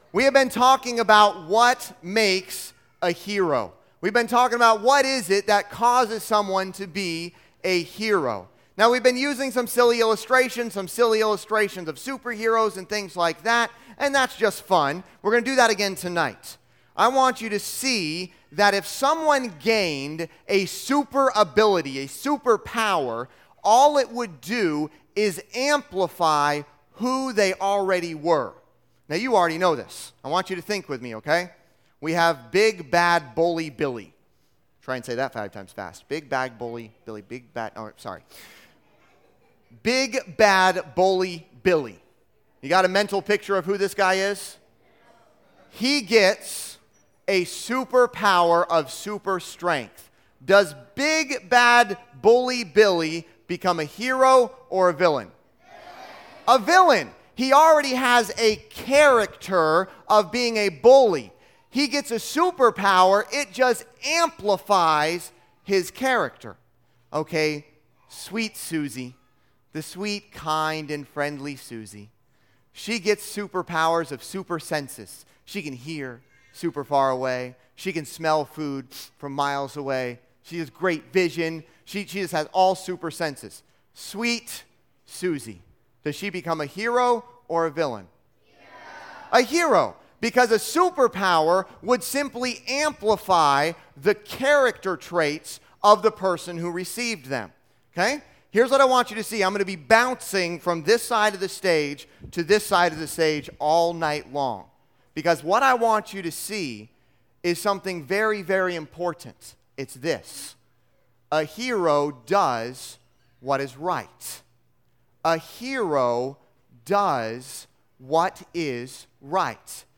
Listen to Message
Service Type: Junior Camp